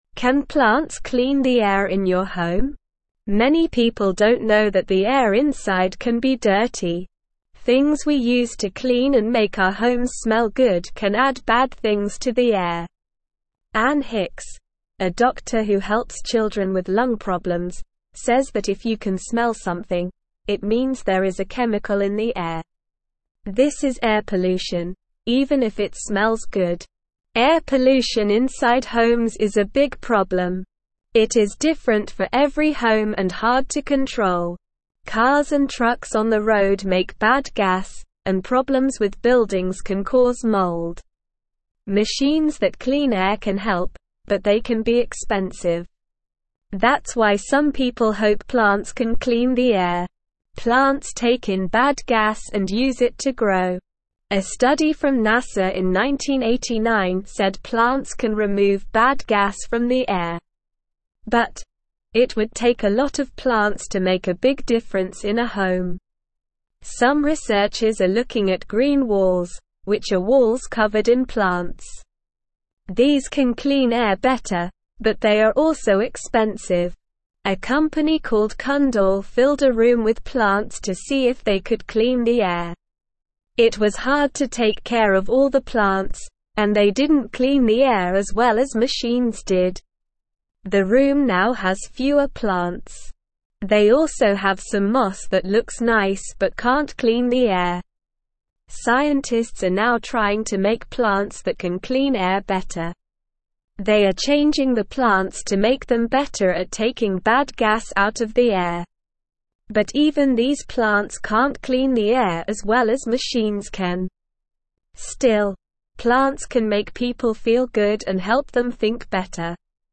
Slow
English-Newsroom-Beginner-SLOW-Reading-Can-Plants-Clean-Our-Home-Air.mp3